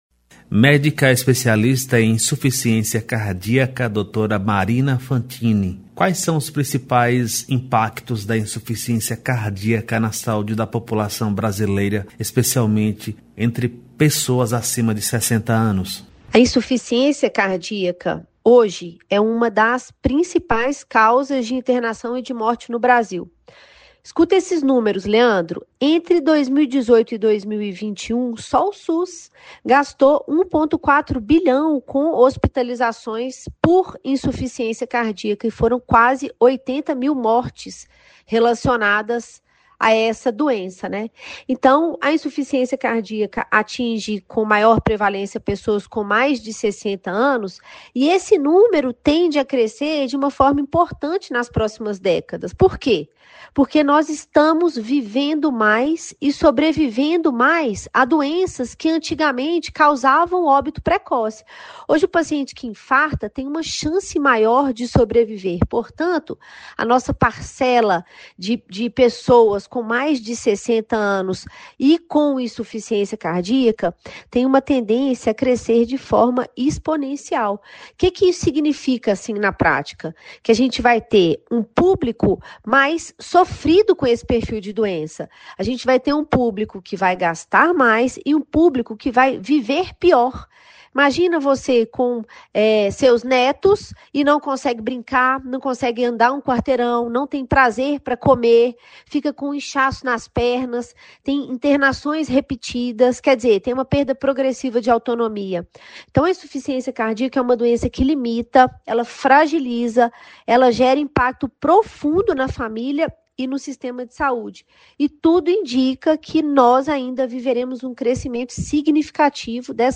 Médica especialista em insuficiência cardíaca